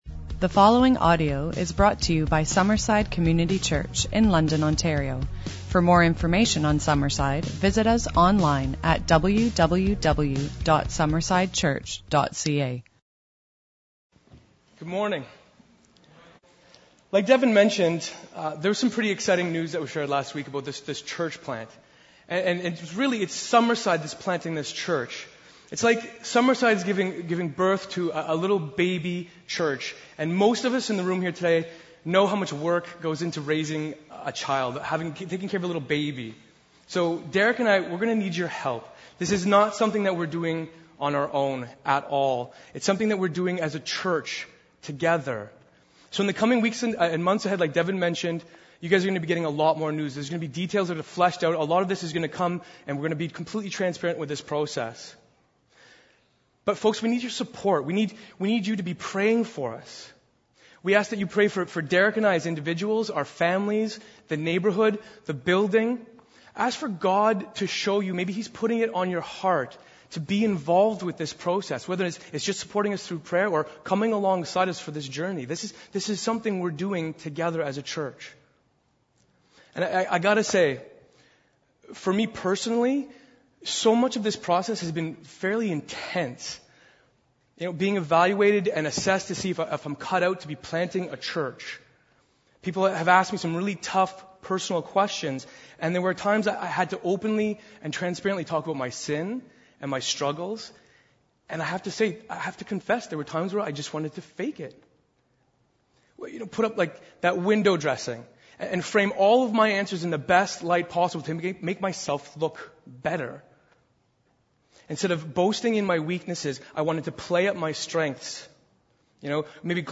As we’ve been studying Paul’s letter to the Philippians, we’ve been examining how we, as Christians, are being made new in Christ and through Christ. In this week’s sermon, we will explore three elements of…